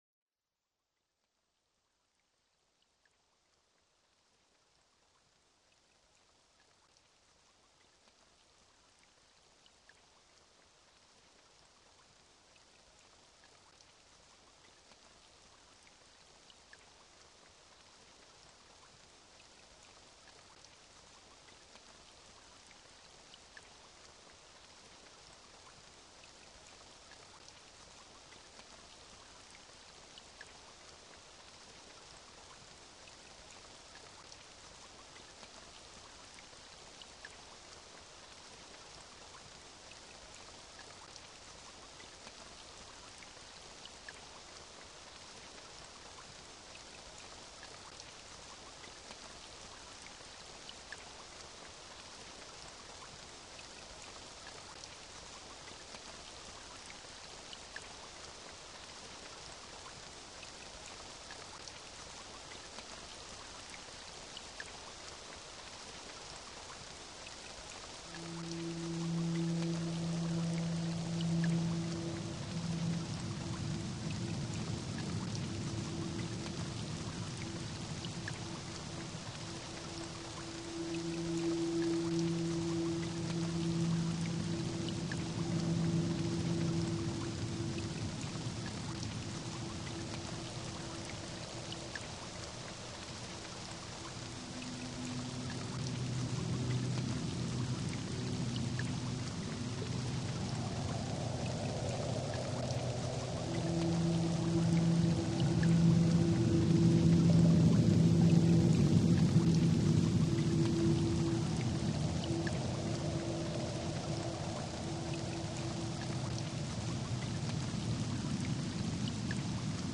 Wind-Rain-Drops-and-Drones.mp3